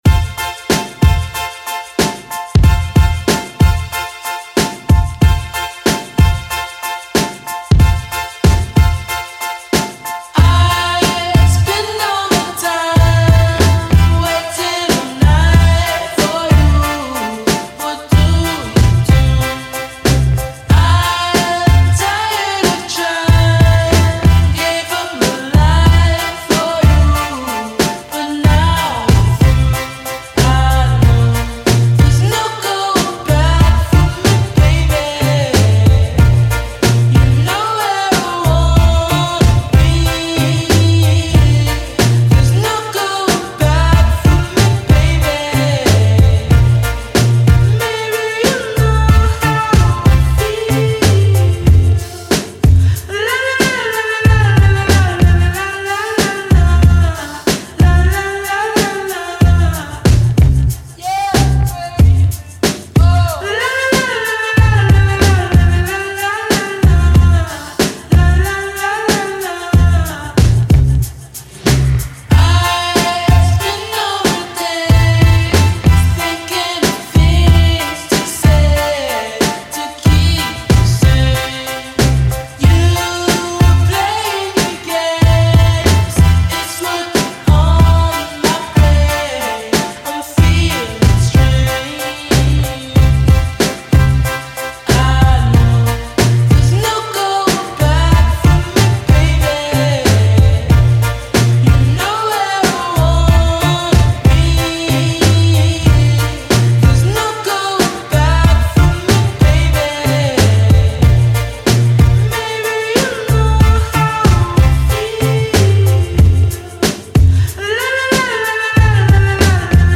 dream pop single